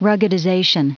Prononciation du mot : ruggedization
ruggedization.wav